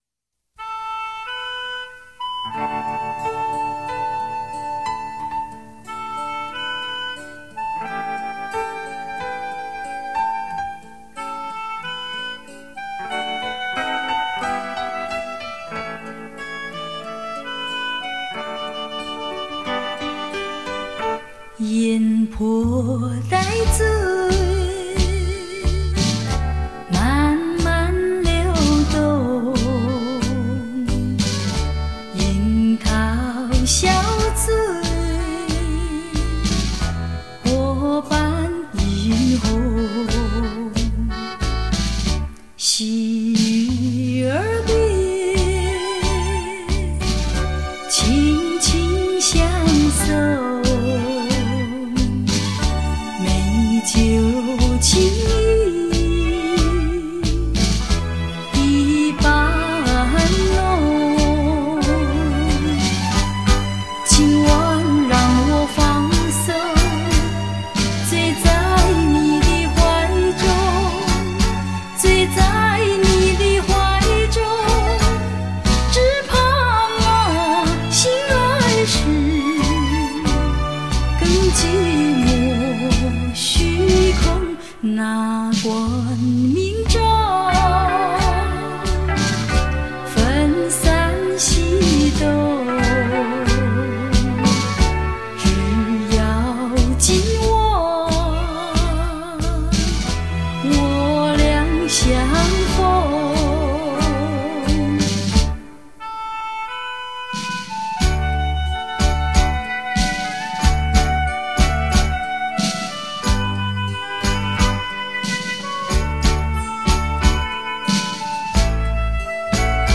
怀旧的歌声，让时空倒转，让身心放松，细细的品味，静静的聆听，永恒的情怀，美好的回忆，听昨日之歌。